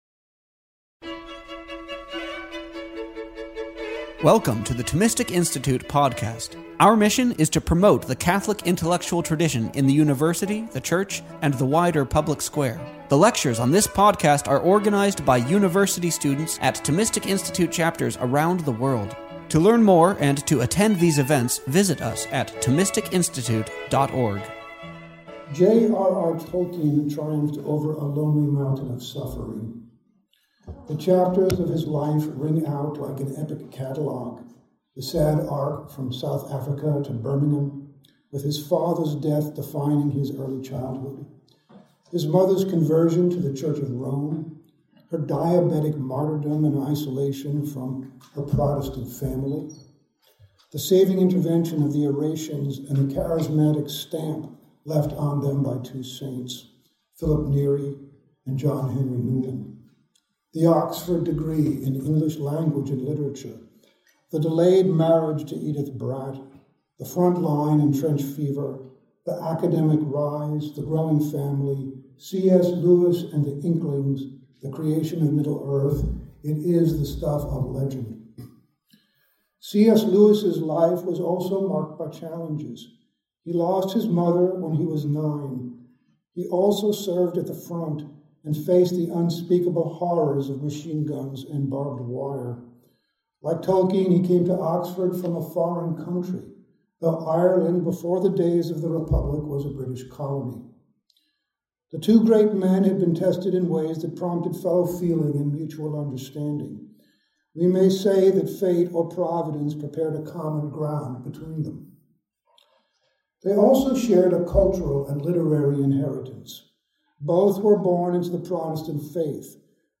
This lecture was given to UC Berkeley on February 8, 2021.